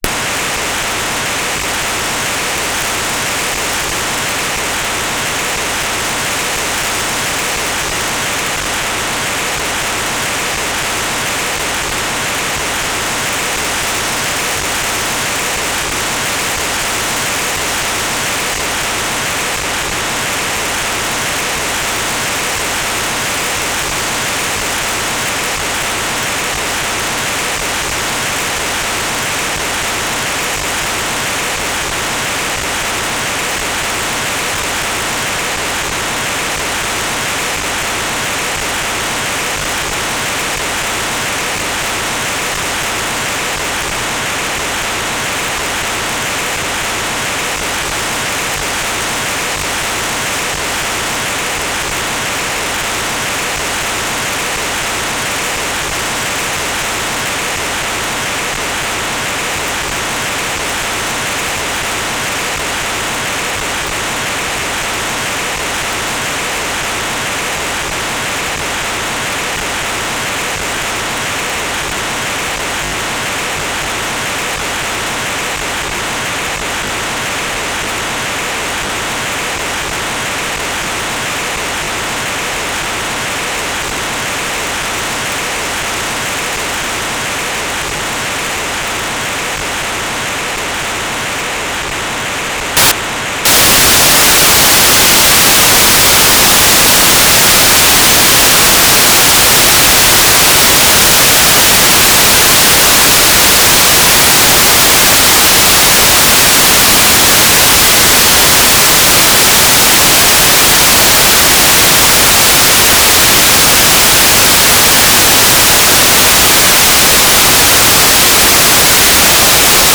"transmitter_description": "Telemetry",